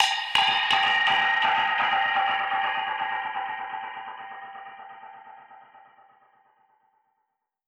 Index of /musicradar/dub-percussion-samples/125bpm
DPFX_PercHit_E_125-10.wav